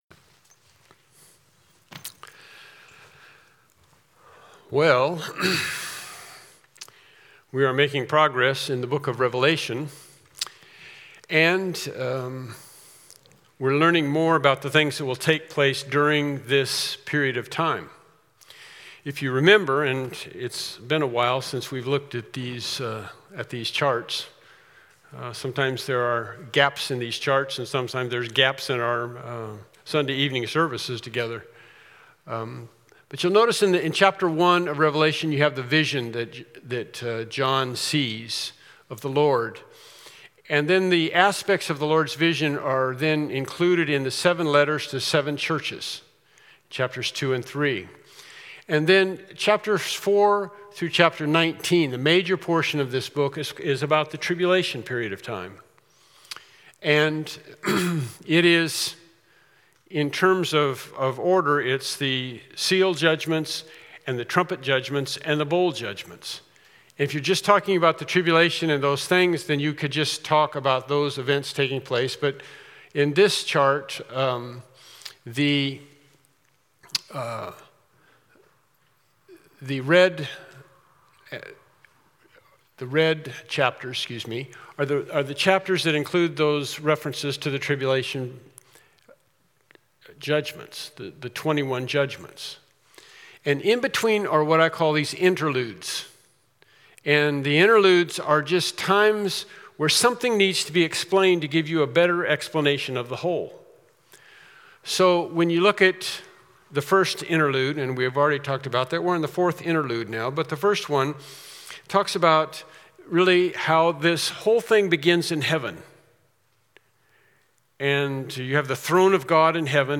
Passage: Revelation 12 Service Type: Evening Worship Service « “Christ in You